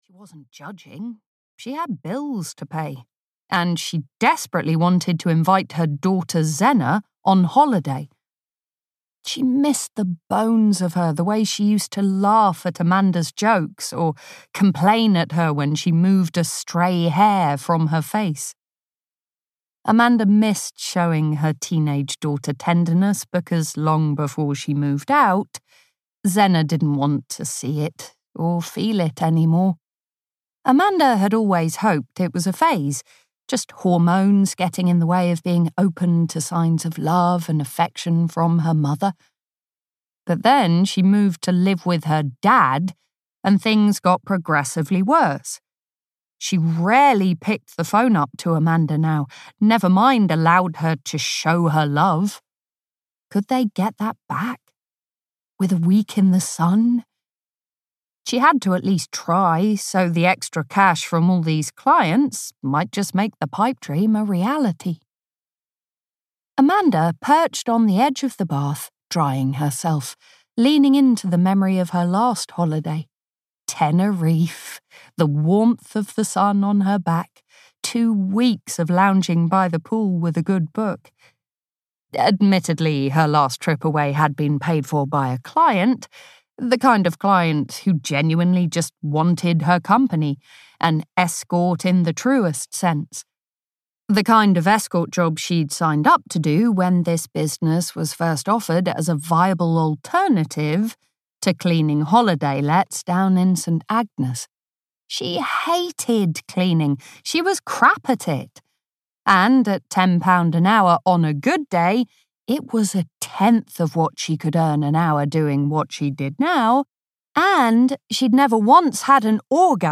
Her Best Friend's Secret (EN) audiokniha
Ukázka z knihy